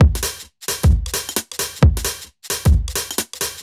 Index of /musicradar/uk-garage-samples/132bpm Lines n Loops/Beats
GA_BeatD132-07.wav